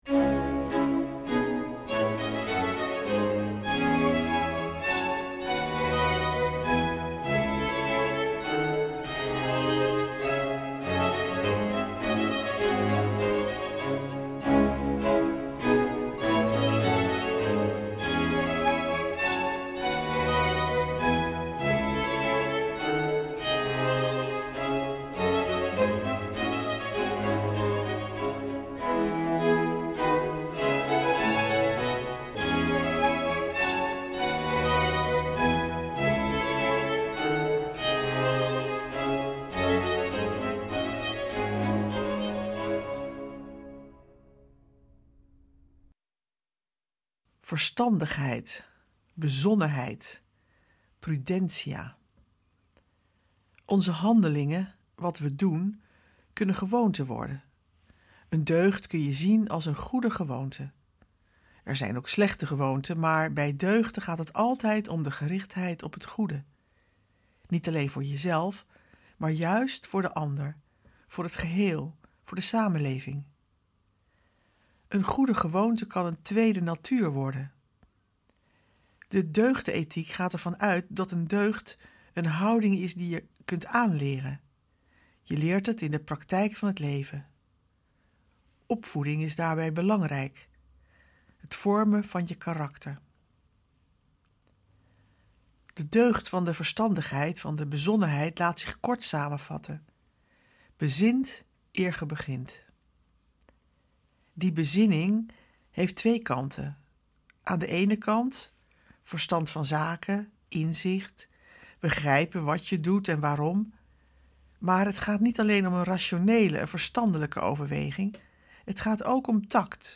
Meditaties Op Weg Naar Pasen 2023